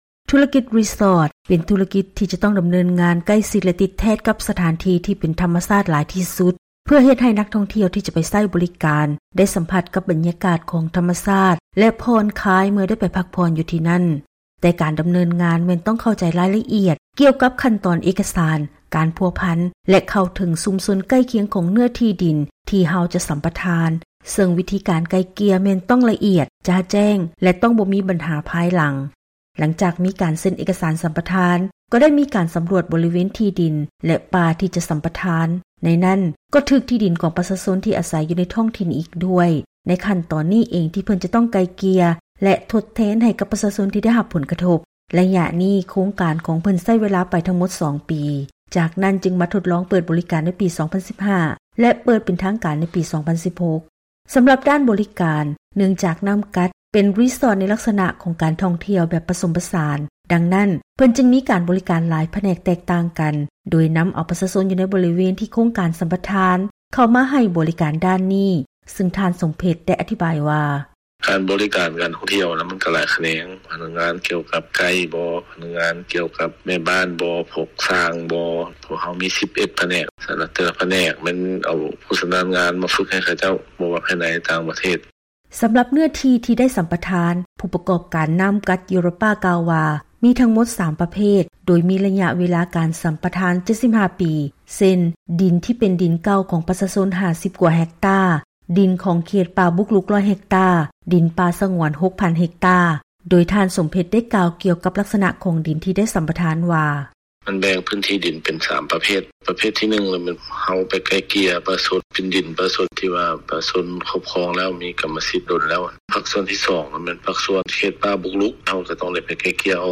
ເຊີນຮັບຟັງລາຍງານກ່ຽວກັບ ຂັ້ນຕອນການດໍາເນີນງານ ແລະບໍລິຫານ ຂອງທຸລະກິດ ຣີສອດ.